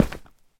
Minecraft / step / stone1.ogg
Current sounds were too quiet so swapping these for JE sounds will have to be done with some sort of normalization level sampling thingie with ffmpeg or smthn 2026-03-06 20:59:25 -06:00 9.9 KiB Raw History
stone1.ogg